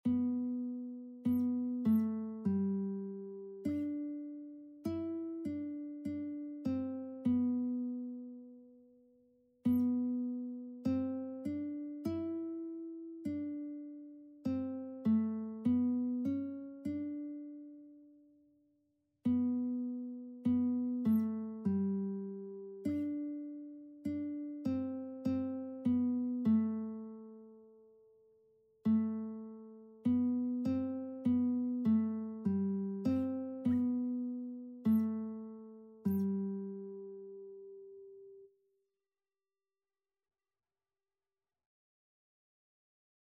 Christian Christian Lead Sheets Sheet Music Abide With Me (Eventide)
G major (Sounding Pitch) (View more G major Music for Lead Sheets )
4/4 (View more 4/4 Music)
Classical (View more Classical Lead Sheets Music)